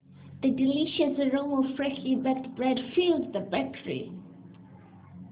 joyfully.amr